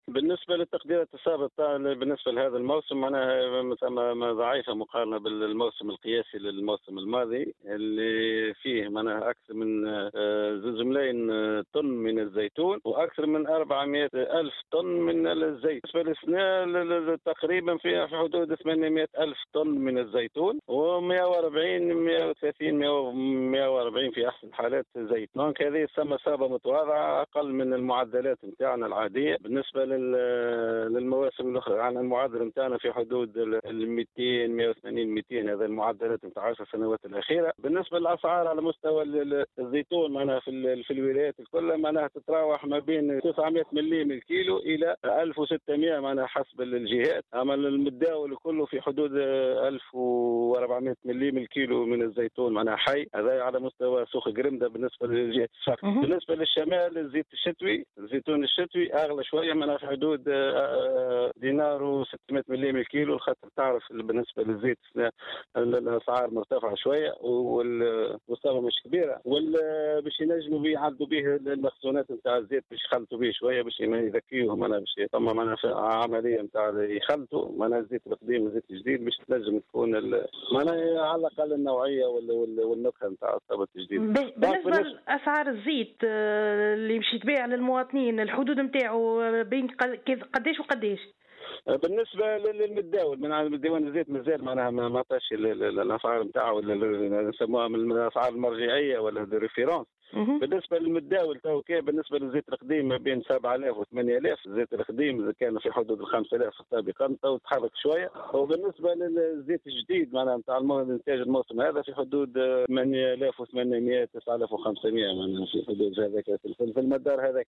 أكد مدير عام الانتاج الفلاحة بوزارة الفلاحة عبد الفتاح سعيد، اليوم السبت في تصريح لـ "الجوهرة أف أم" أن الديوان الوطني لم يصدر اي بلاغ بخصوص تسعيرة زيت الزيتون للموسم الحالي وحتى يكون السعر المرجعي للسوق.